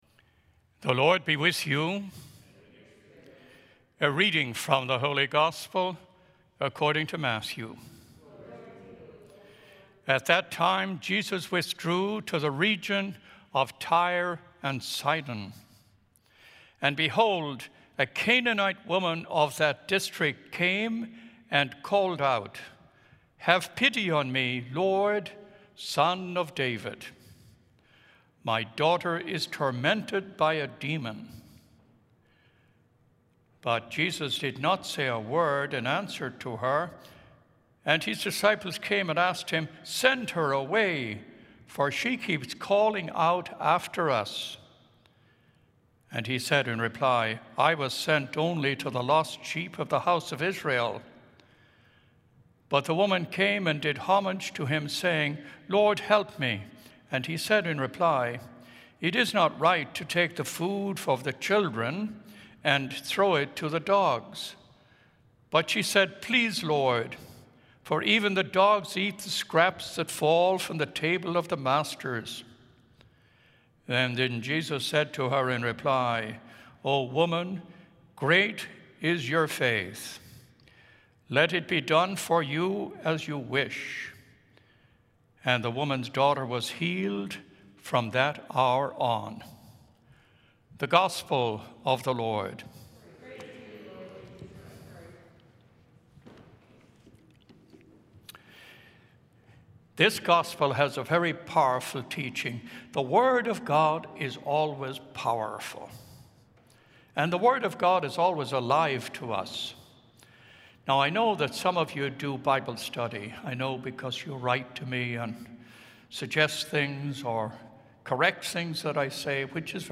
Gospel and Homily Podcasts